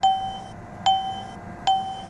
ATM Ding
Category ⚡ Sound Effects